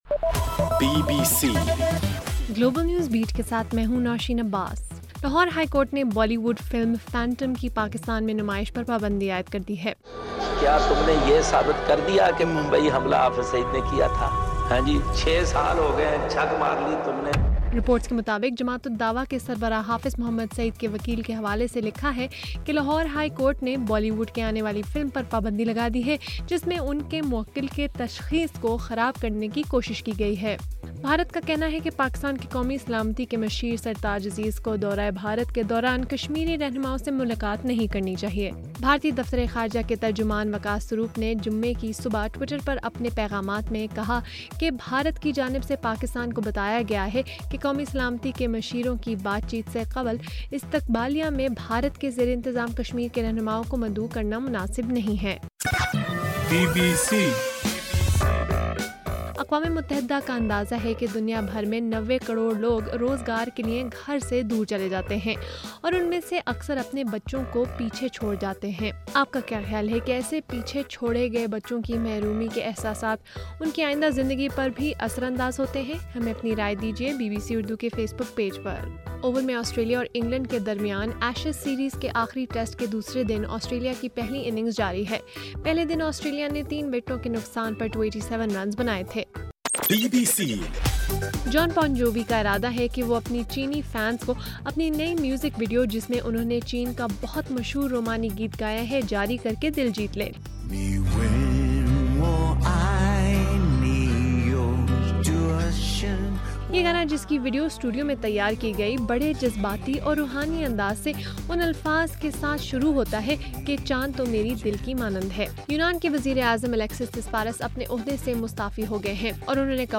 اگست 21: رات 11 بجے کا گلوبل نیوز بیٹ بُلیٹن